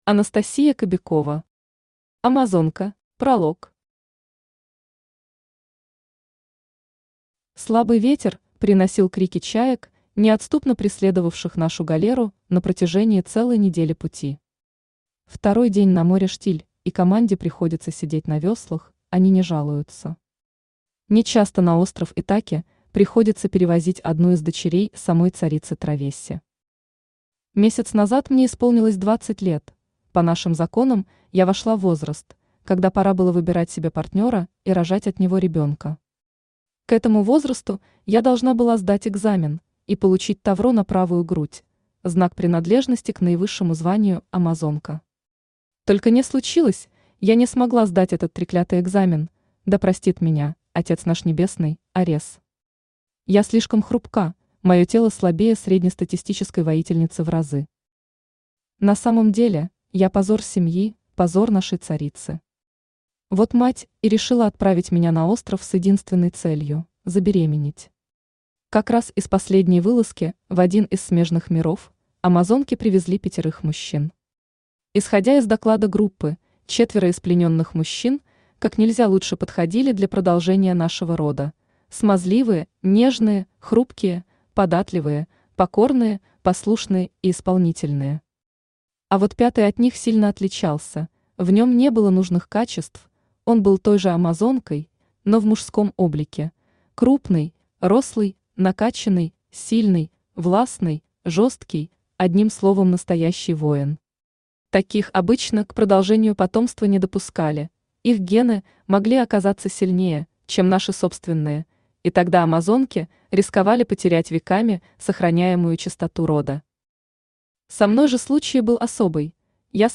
Аудиокнига Амазонка | Библиотека аудиокниг
Aудиокнига Амазонка Автор Анастасия Кобякова Читает аудиокнигу Авточтец ЛитРес.